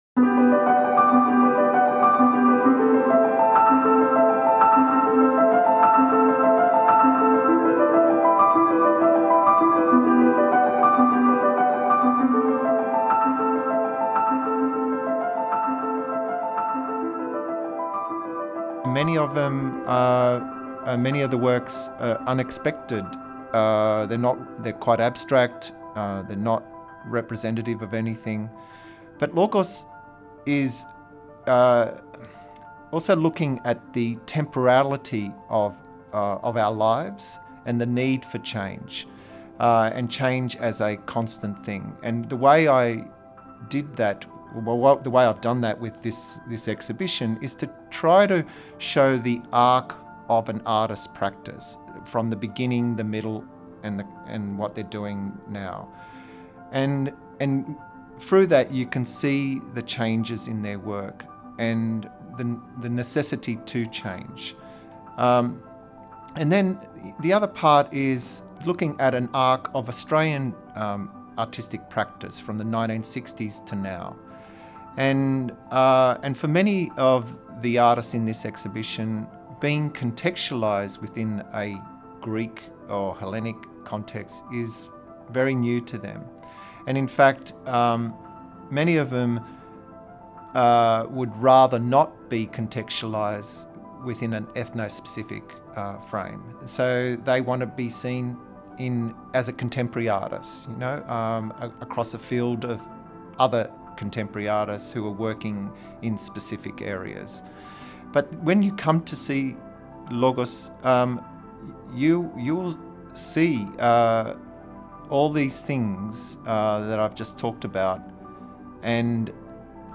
σε συνέντευξή του που μεταδόθηκε στην ραδιιοφωνική εκπομπή “Νυκτερινοί Περίπατοι”